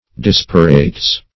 Search Result for " disparates" : The Collaborative International Dictionary of English v.0.48: Disparates \Dis"pa*rates\, n. pl. Things so unequal or unlike that they can not be compared with each other.